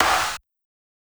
SNARE_GRITS.wav